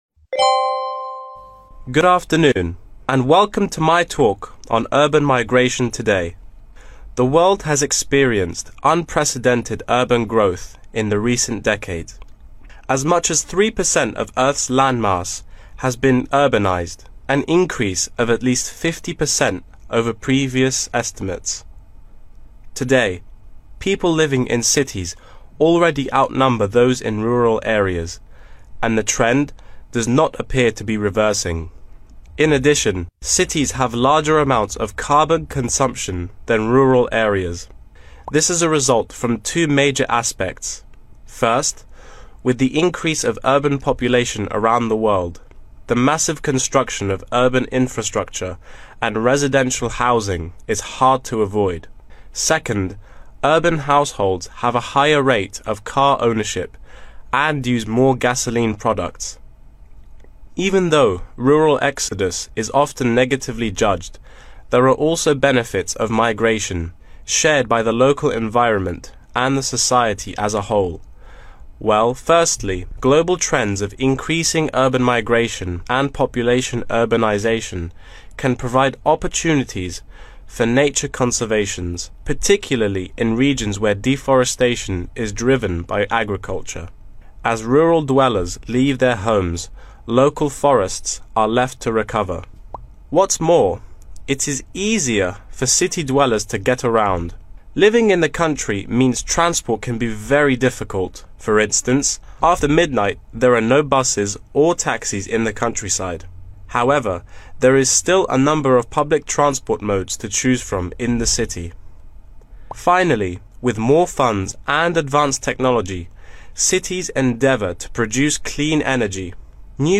Urban migration discussion: Đề thi IELTS LISTENING (actual test) kèm đáp án & transcript with location